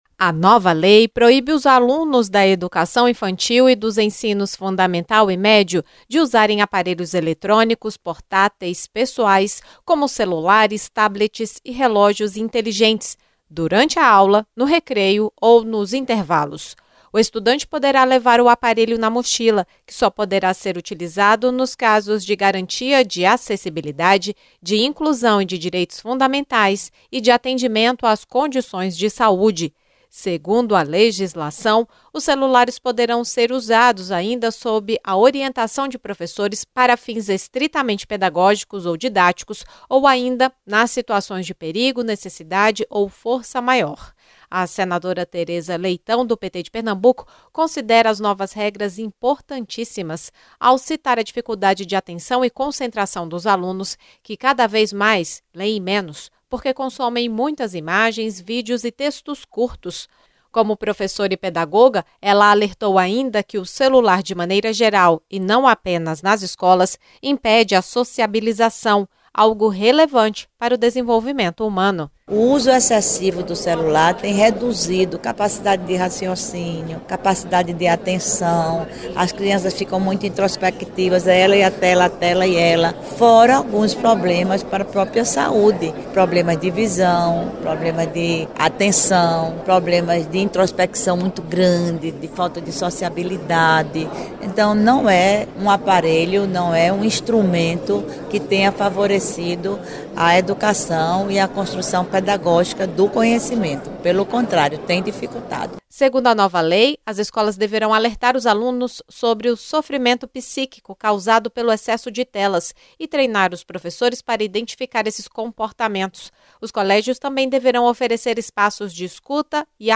A senadora Teresa Leitão (PT-PE) defendeu a restrição ao citar problemas recorrentes, como falta de atenção, concentração e sociabilidade por parte dos estudantes.